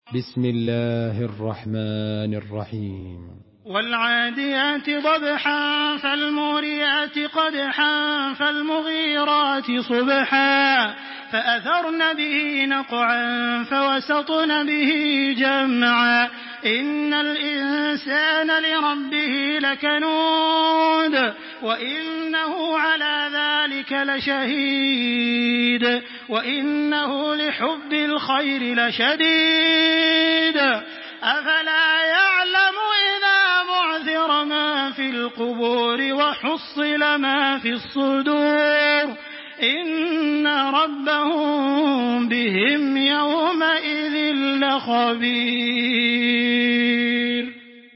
تراويح الحرم المكي 1426
مرتل حفص عن عاصم